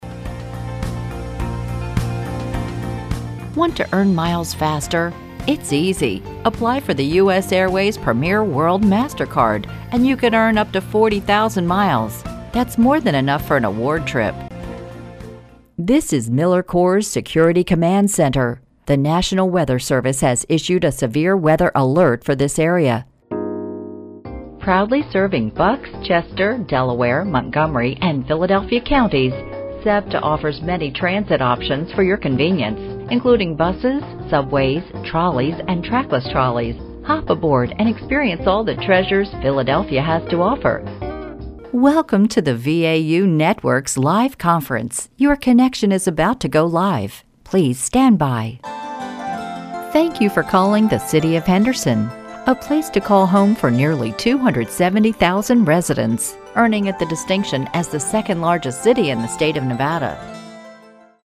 Sprechprobe: Sonstiges (Muttersprache):
Mid range voice with energy, conversational, even character voices